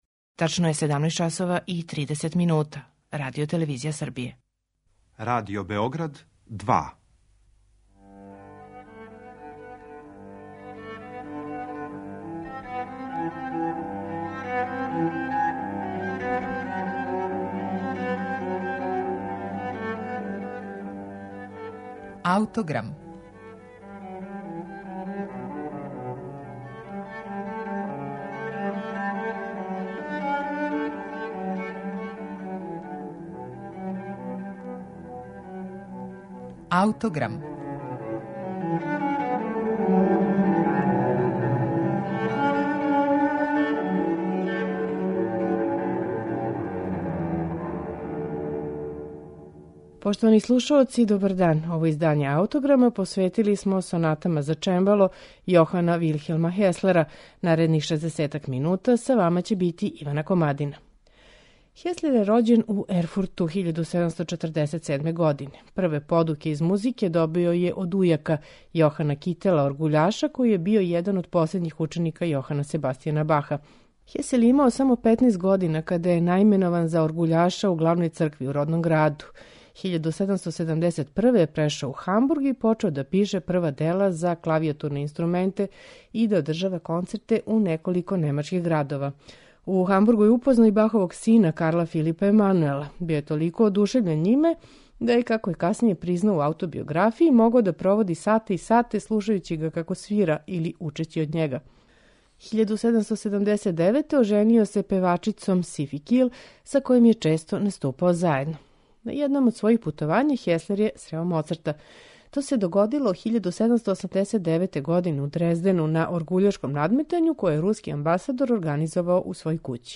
Сонате за чембало Јохана Вилхелма Хеслера
оствареној на чемблу израђеном 1773. године у Лондону